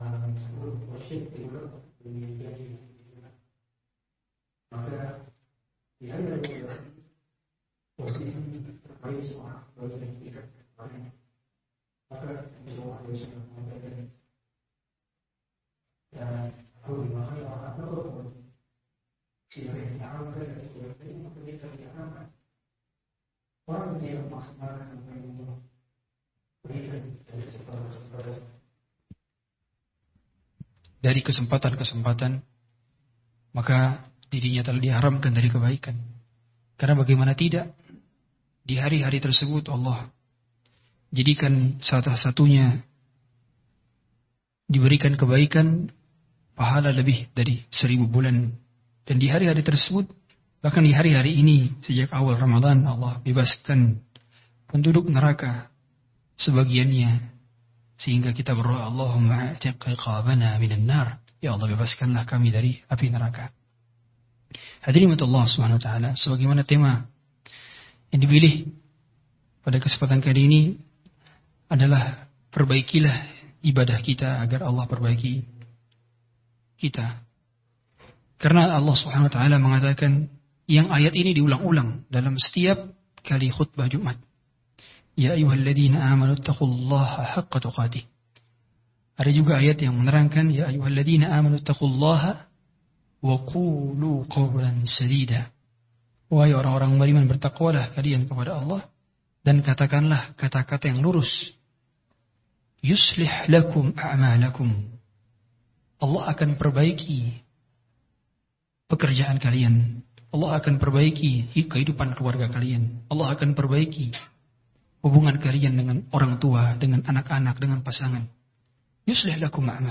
AUDIO KAJIAN